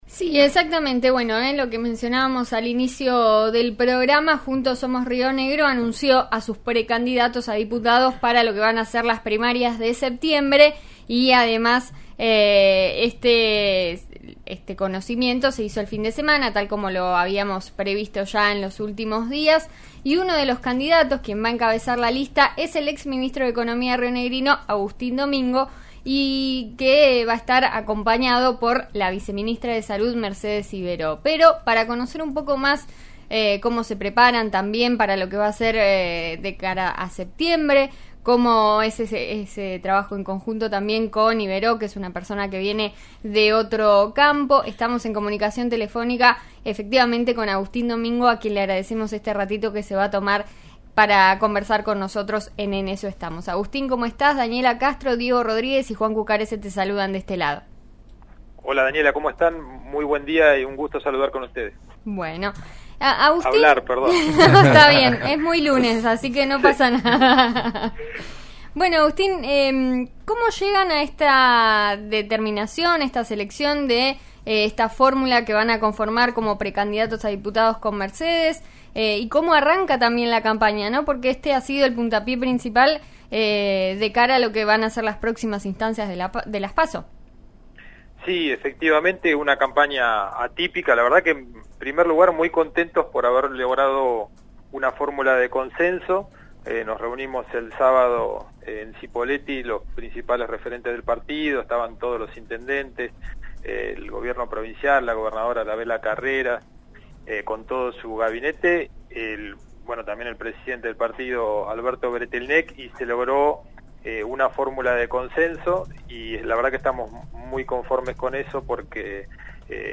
El exministro de Economía dialogó esta mañana con RN Radio.
El exministro de Economía y actual candidato a diputado por Juntos Somos Río Negro, Agustín Domingo, conversó esta mañana con el programa En Eso Estamos que se emite por RN Radio. Durante la charla, Domingo contó cómo se prepara de cara a las próximas elecciones legislativas.